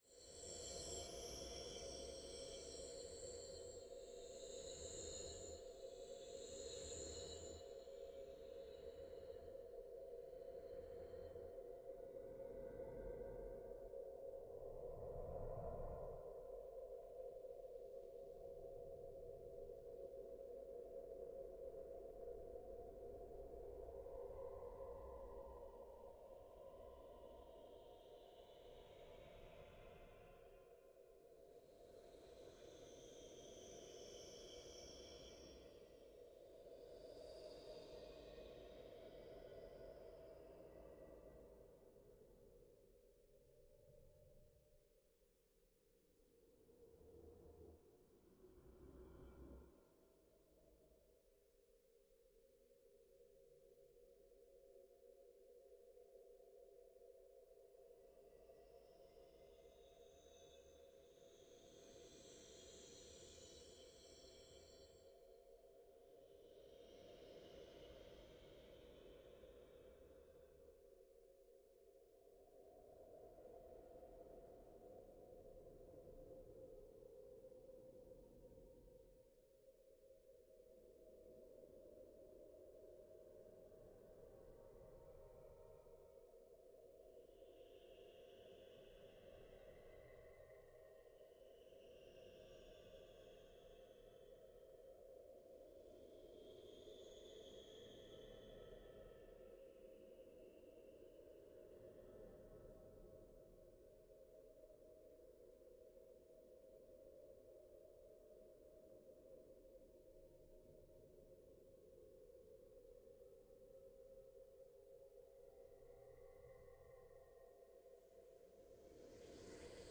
drones